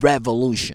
REVOLUTION.wav